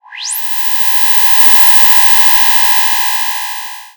SF・空想機械